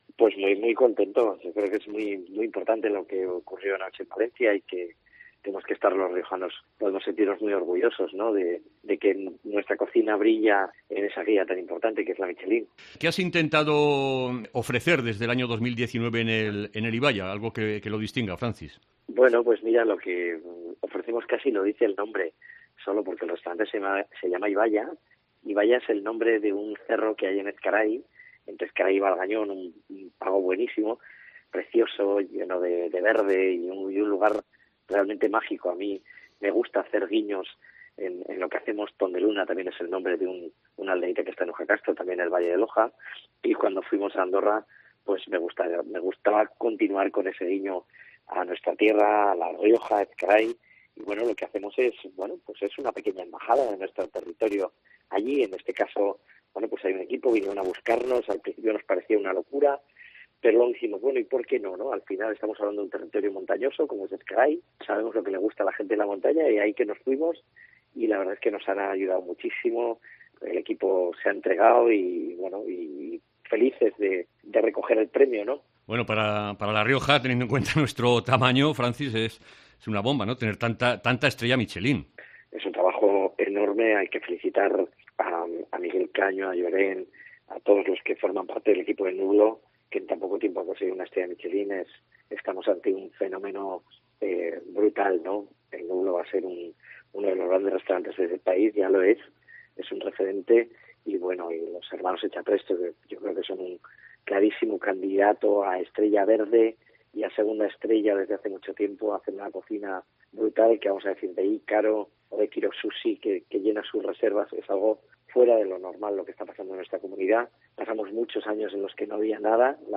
Entrevista a Francis Paniego